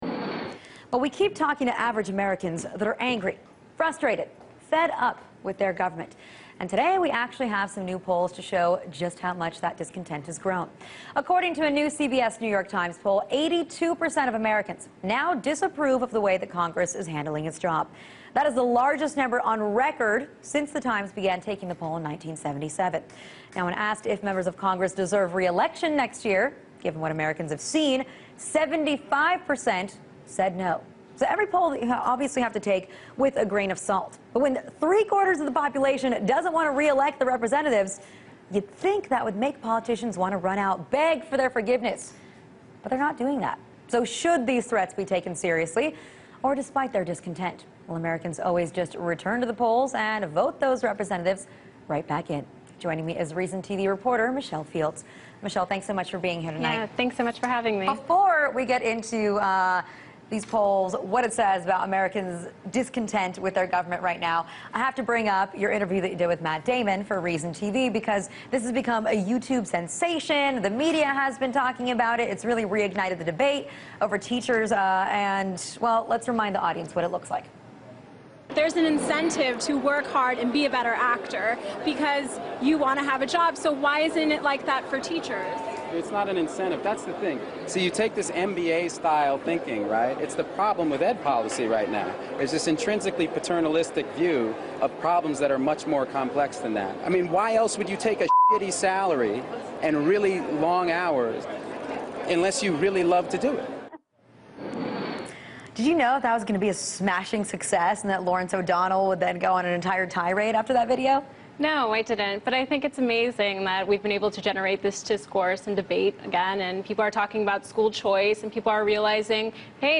Journalist Michelle Fields discusses a CBS/New York Times poll that 82% of Americans dissaprove of Congress and her viral hit interview with Matt Damon on the Alyona Show.